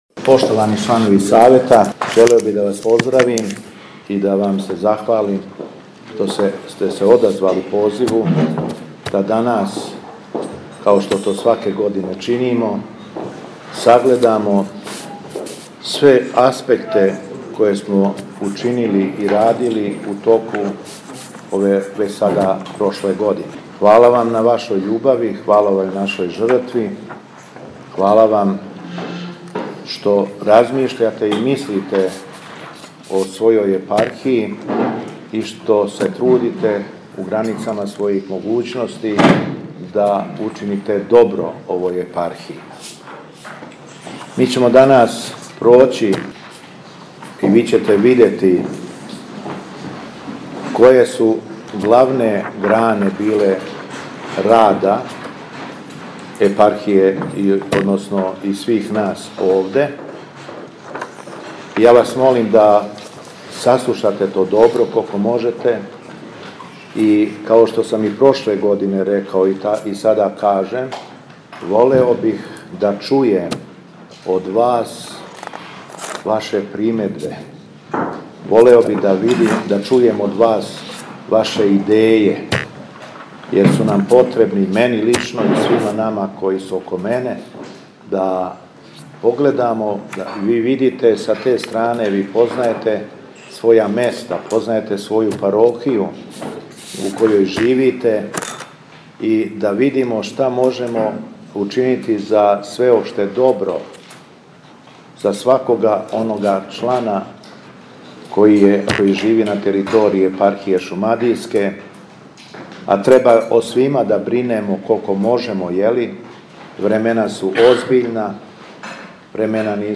На седници Епархијског савета уводну реч кроз експозе изнео је Његово Високопреосвештенство Митрополит шумадијски и Архиепископ крагујевачки г. Јован.